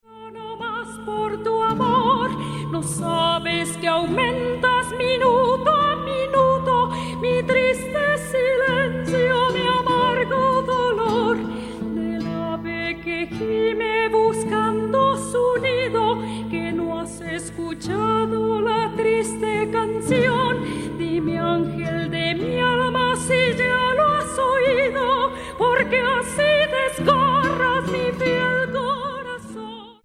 Canciones populares y danzas mexicanas del siglo XIX
guitarra séptima y sexta